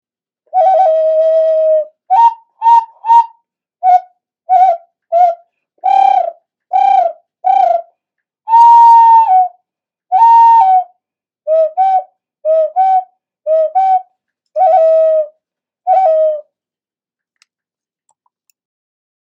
Jaguar transverse whistle, best musical instrument flute mimics bird sounds
Jaguar transverse whistle
Our Jaguar transverse whistle is a hand crafted musical instrument made one at a time, these modified flutes produces sharp sound that can sound like wild birds. This instrument  is constructed the same way as the original archeological piece; with rare black clay and all natural appearance enhancer.